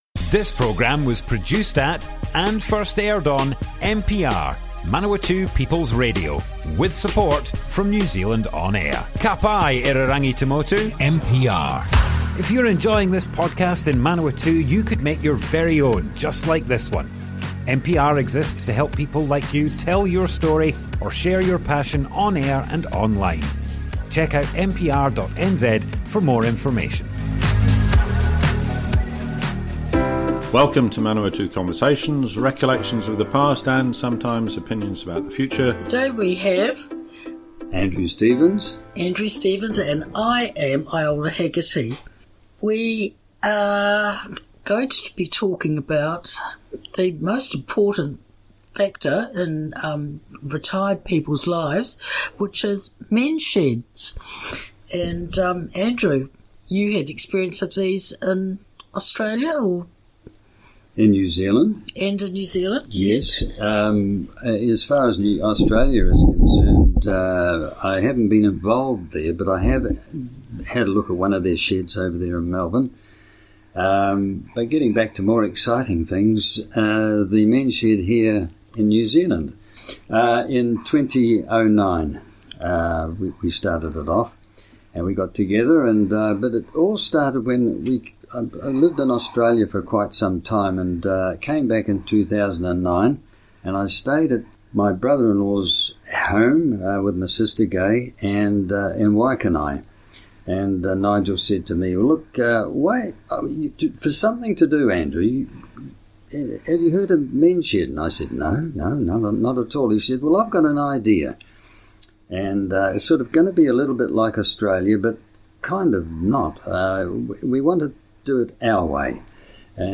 Manawatu Conversations More Info → Description Broadcast on Manawatu People's Radio, 9th July 2024.
oral history